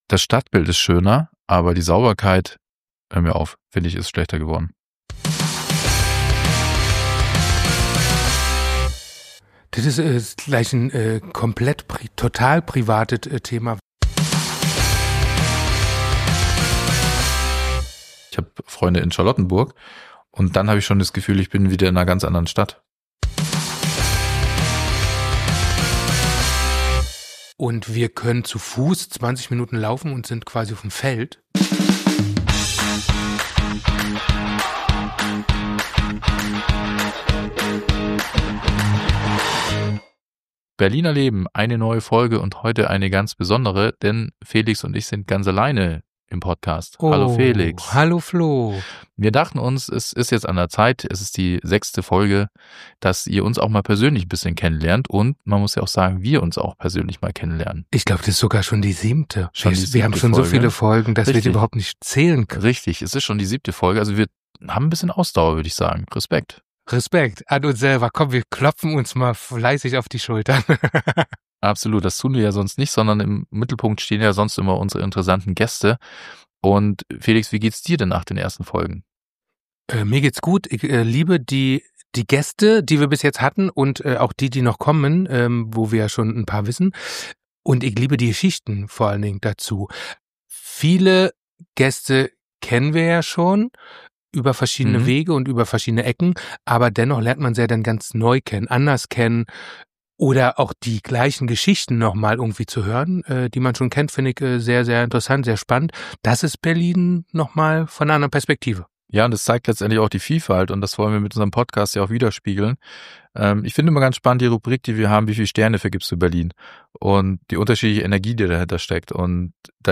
Ehrlich, sympathisch, mit Augenzwinkern, so klingt Berlin, wenn sich die Macher von berlin:er:leben mal selbst befragen.